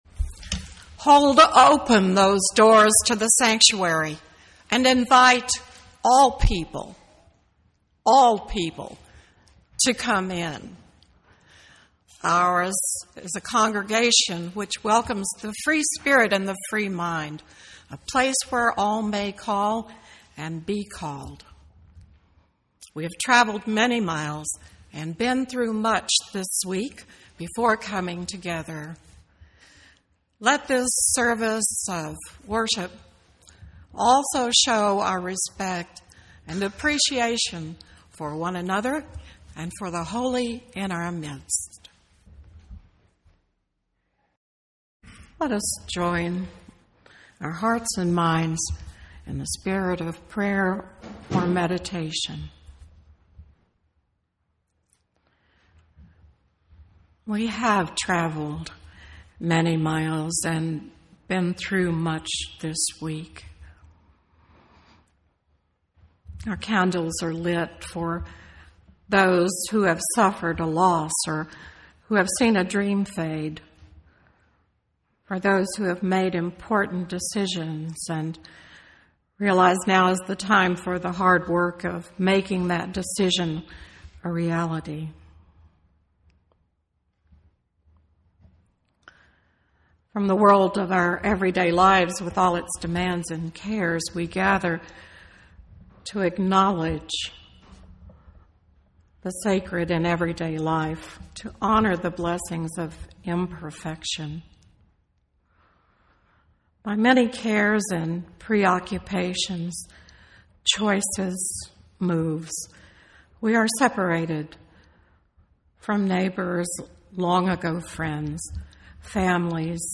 2008 The text of this sermon is unavailable but you can listen to the sermon by clicking the play button above.